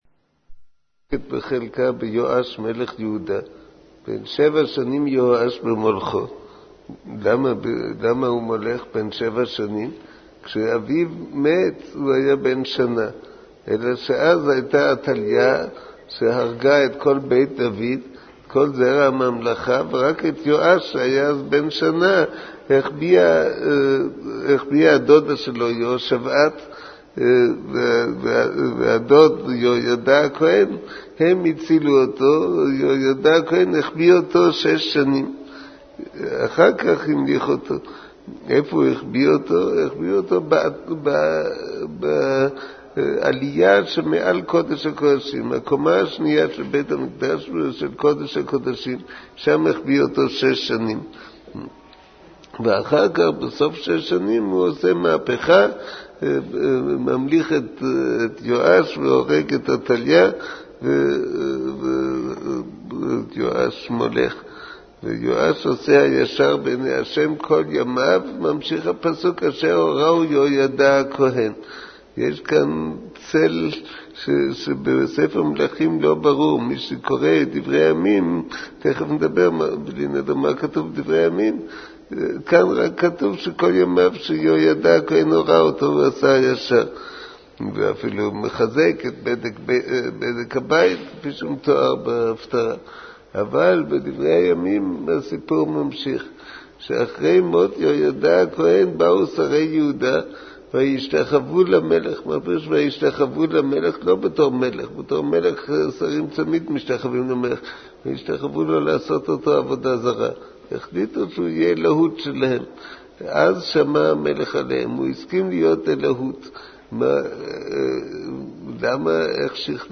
שיחה לפרשת פקודי
מעביר השיעור: מו"ר הרב אביגדר נבנצל